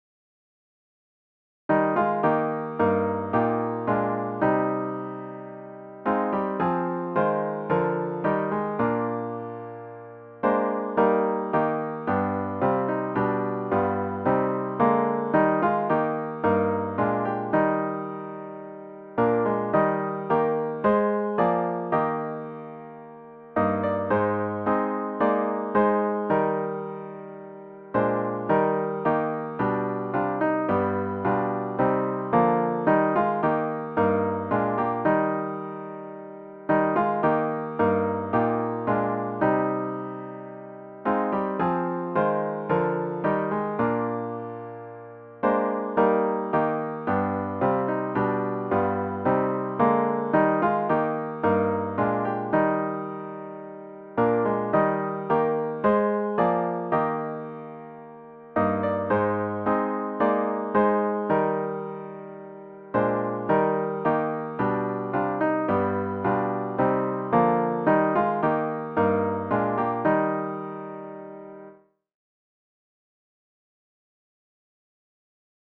OPENING HYMN   “This Is My Father’s World”   GtG 370
zz-370-This-Is-My-Fathers-World-2vs-piano-only.mp3